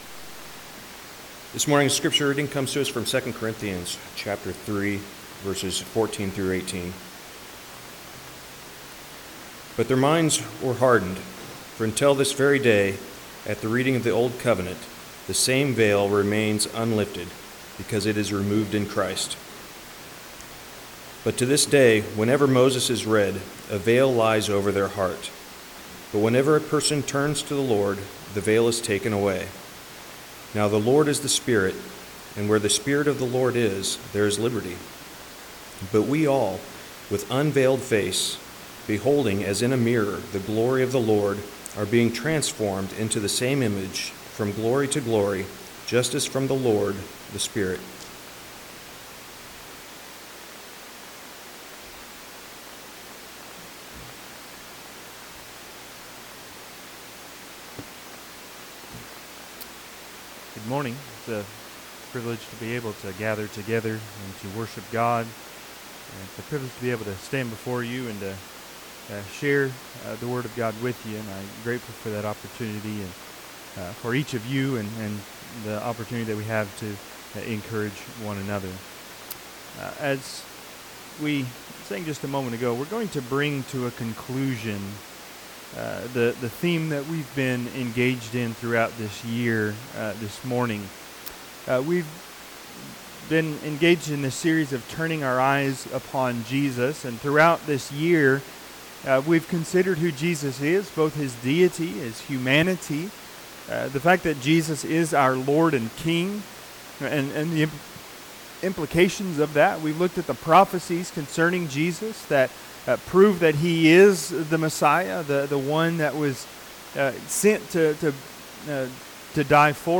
2 Corinthians 3:14-18 Service Type: Sunday AM The finale of the Turn Your Eyes Upon Jesus series 2025.